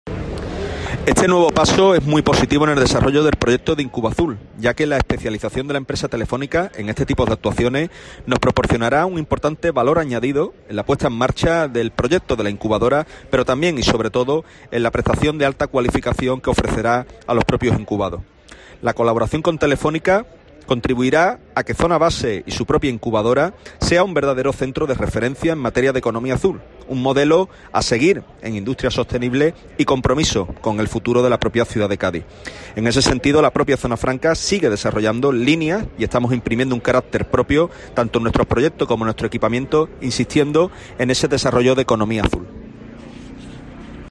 Fran González, Delegado del Estado para la Zona Franca de Cádiz habla sobre los nuevos apoyos que reciben